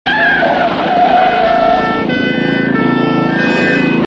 • TIRES SCREECHING AND POLICE SIREN.wav
TYRES_SCREECHING_AND_POLICE_SIREN_pnG.wav